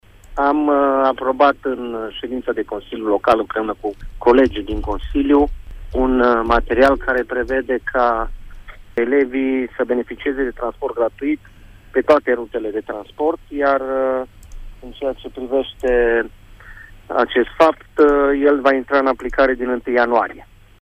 Decizia a fost luată de consilierii locali la ultima şedinţă, a explicat viceprimarul municipului Tîrgu-Mureş, Claudiu Maior: